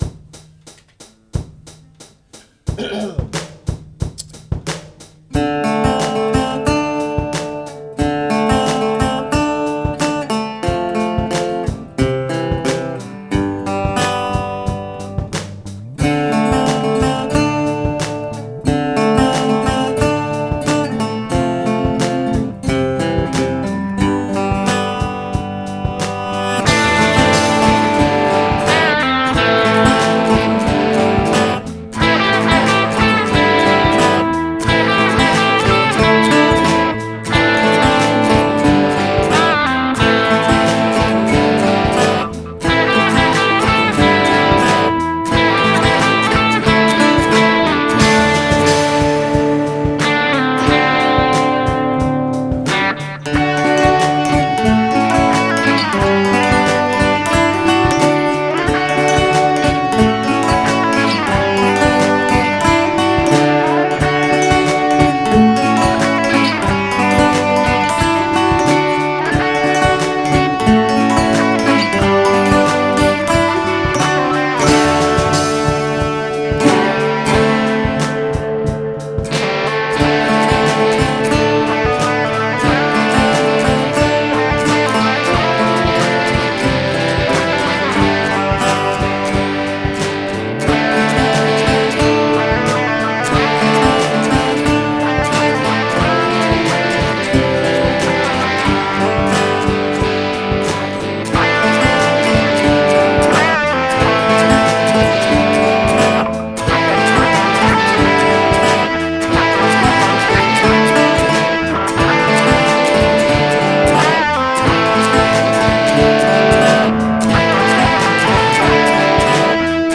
Monday is Guitar Day
I claim copyright anyway, so there; 3. No, I cannot do anything about the general quality of the mix, as I am incompetent.
gee-tar